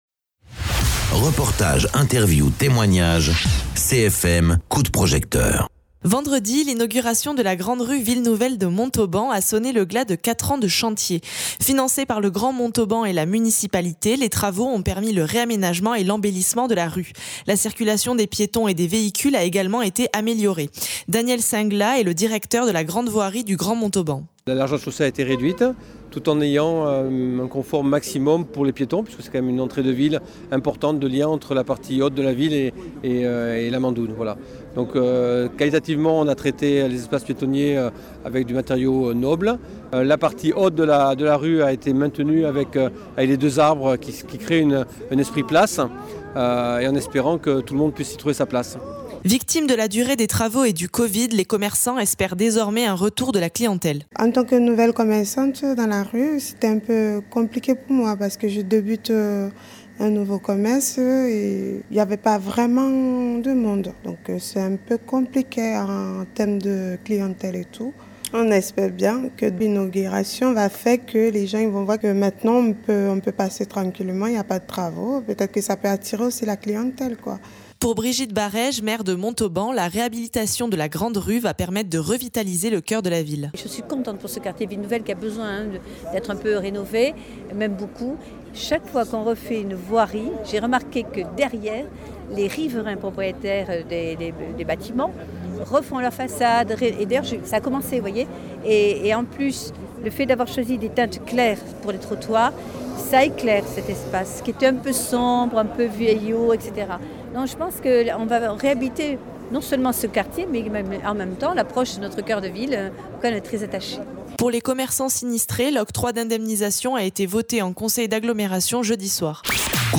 Interviews
Invité(s) : Brigitte Barèges, maire de Montauban